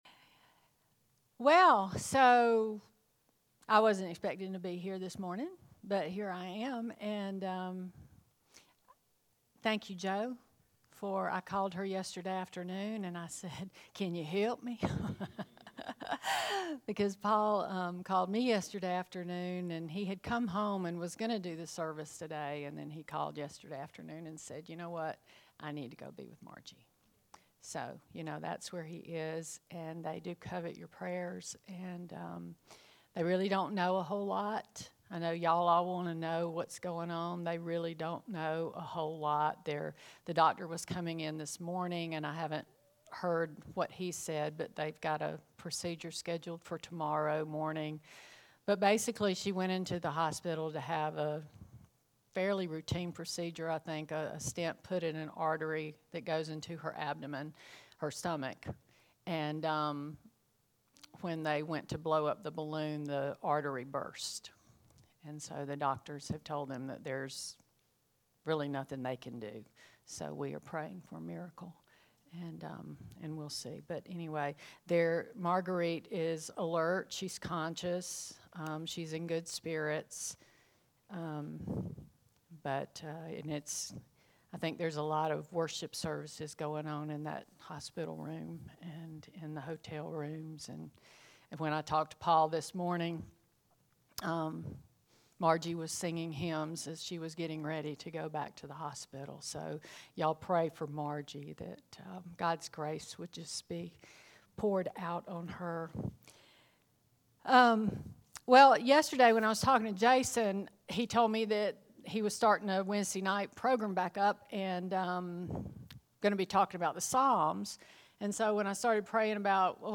Stand Alone Sermon